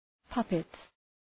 Προφορά
{‘pʌpıt}